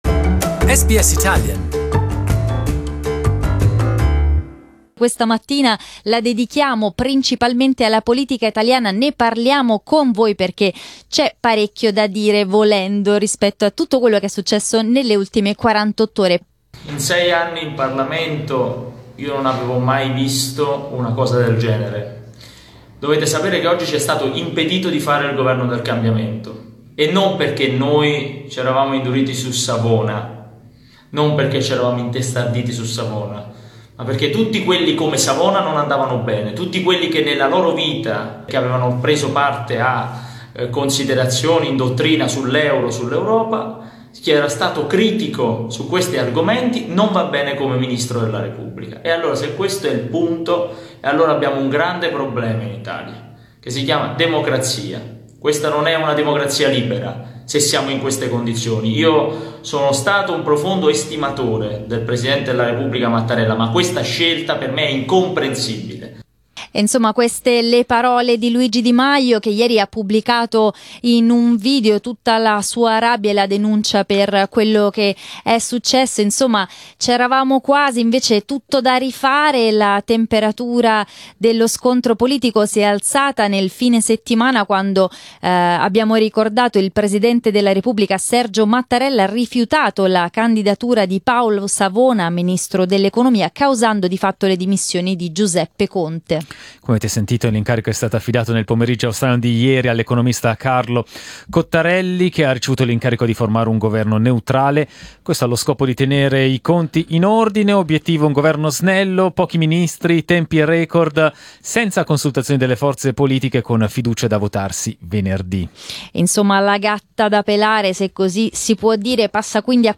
Le opinioni dei nostri ascoltatori sull'operato del Presidente della Repubblica Mattarella in questa fase di incertezza istituzionale.